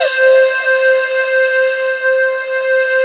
SYNPIPE C4-L.wav